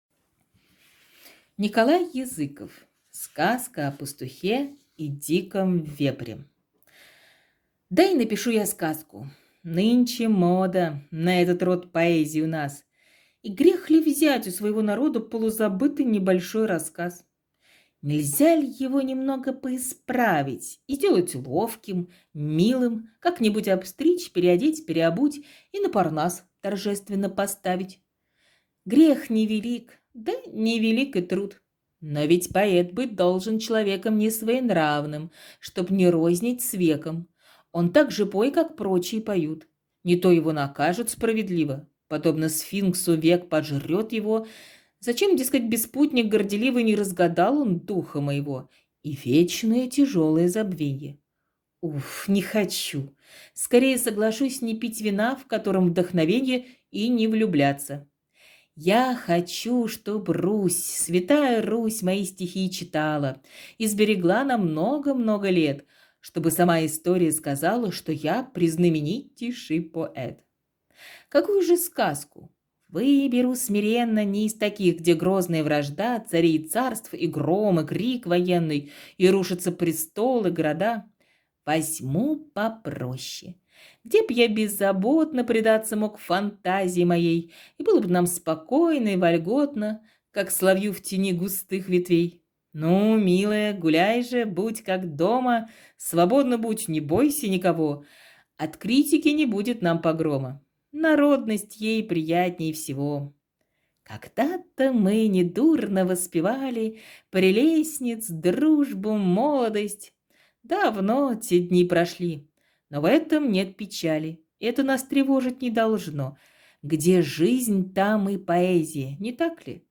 Сказка о пастухе и диком вепре - аудио стихотворение Языкова - слушать